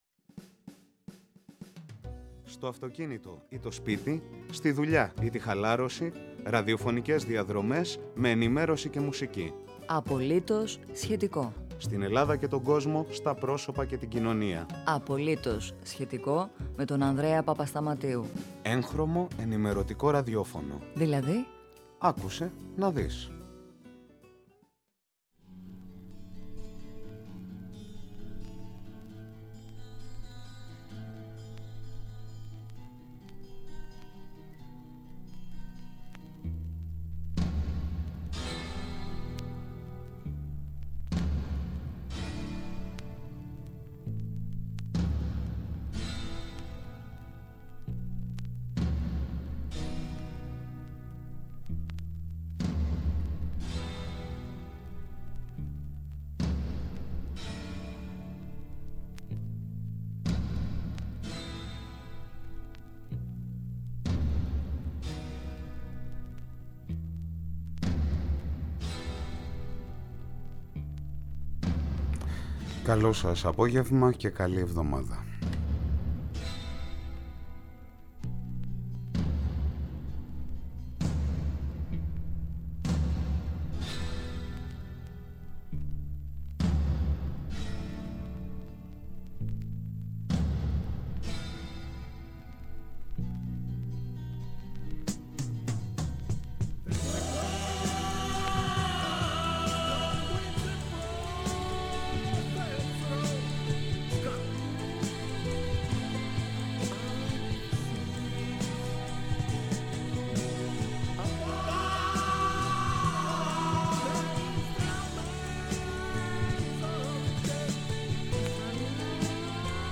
Απογευματινή ενημέρωση και ραδιόφωνο; «Απολύτως … σχετικό»!